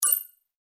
Epic Holographic User Interface Click 4.wav